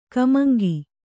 ♪ kamaŋgi